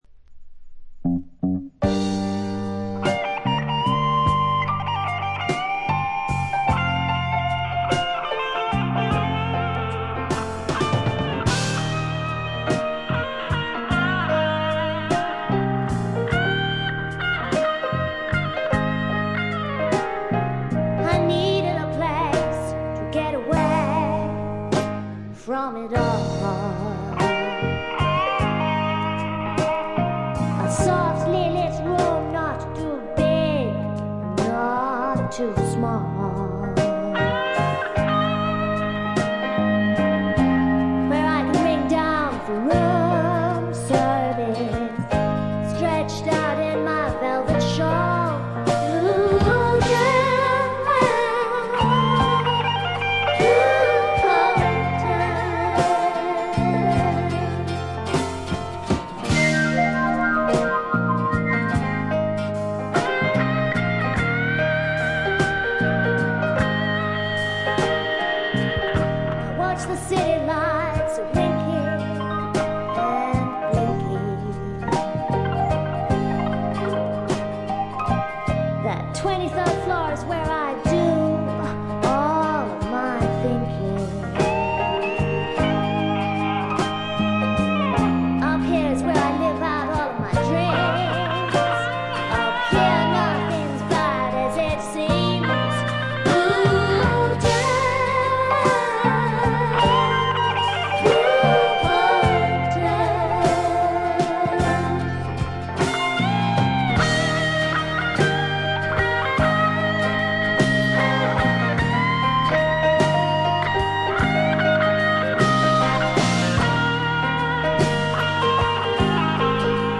静音部で微細なチリプチのみでほとんどノイズ感無し。
曲が良いのと多彩なアレンジで最後まで一気に聴かせます。
試聴曲は現品からの取り込み音源です。